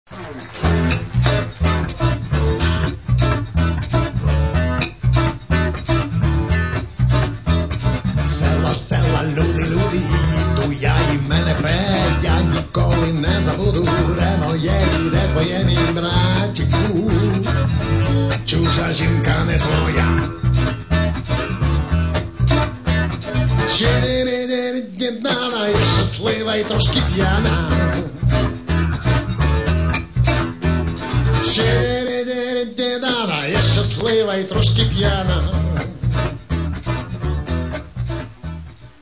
панк-шансон